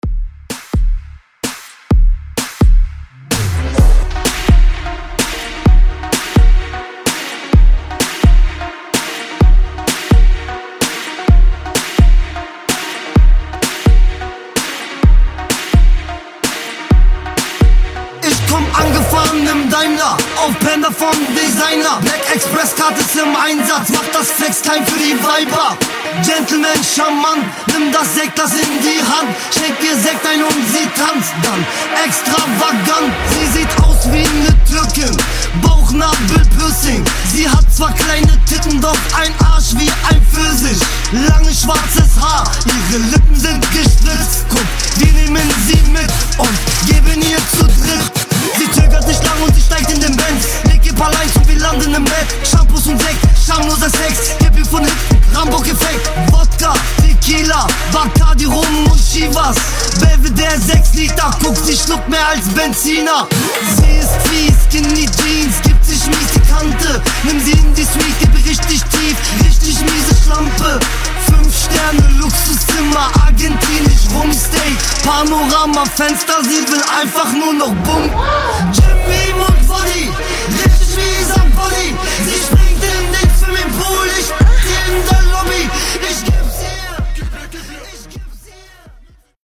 Genre: 90's
Clean BPM: 97 Time